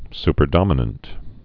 (spər-dŏmə-nənt)